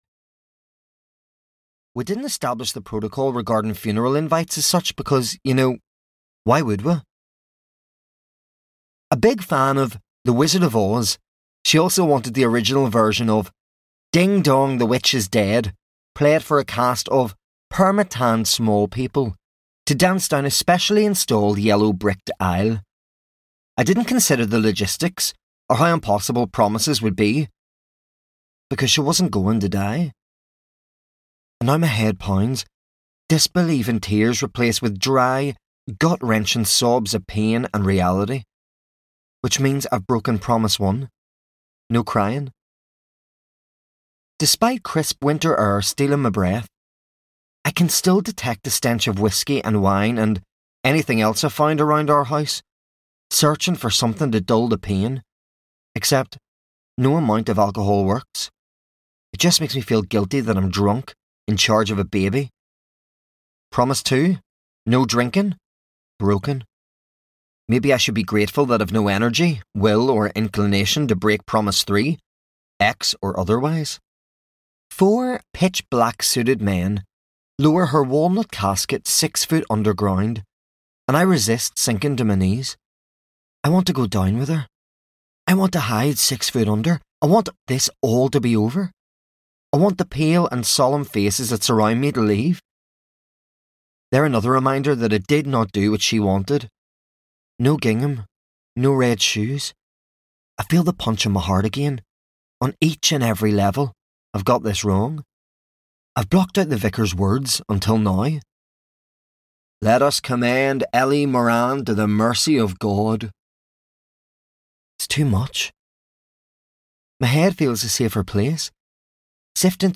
The Lost Wife (EN) audiokniha
Ukázka z knihy